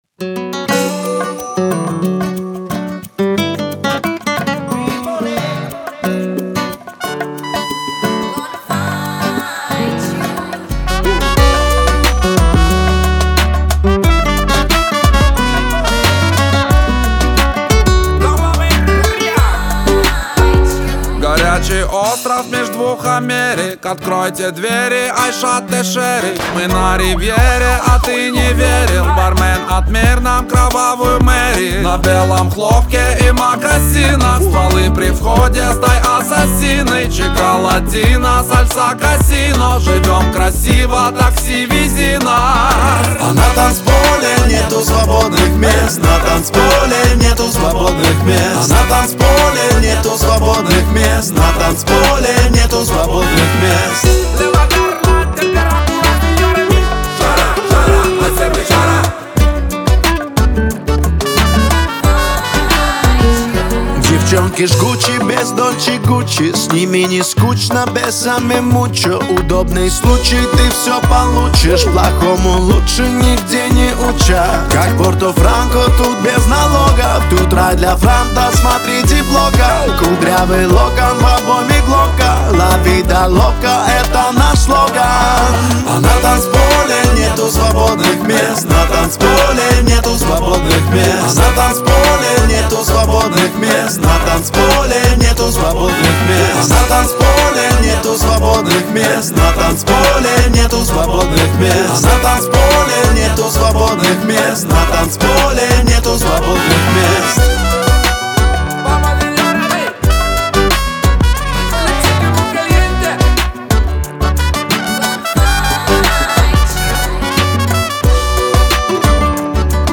это зажигательный трек в жанре поп-рэп